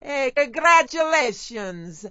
gutterball-3/Gutterball 3/Commentators/Maria/maria_heycongratulations.wav at 58b02fa2507e2148bfc533fad7df1f1630ef9d9b
maria_heycongratulations.wav